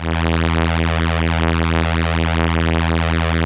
Index of /90_sSampleCDs/Keyboards of The 60's and 70's - CD1/STR_Elka Strings/STR_Elka Violins